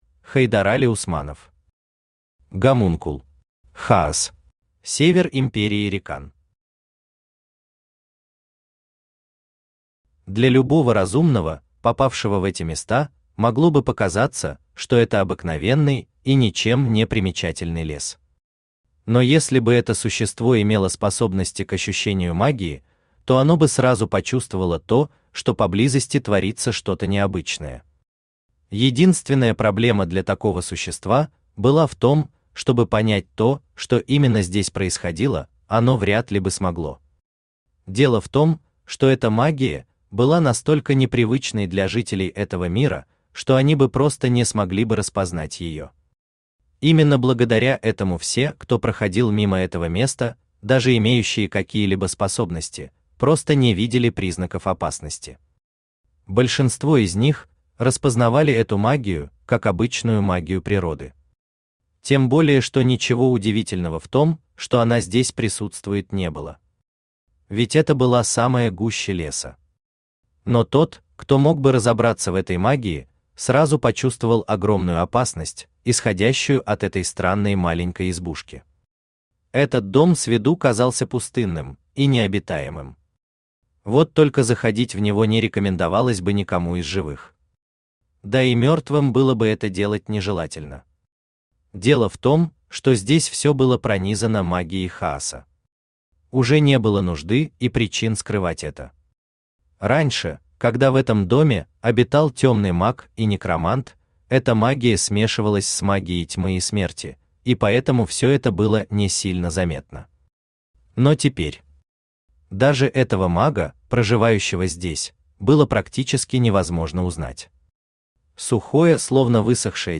Аудиокнига Гомункул. Хаос | Библиотека аудиокниг
Хаос Автор Хайдарали Усманов Читает аудиокнигу Авточтец ЛитРес.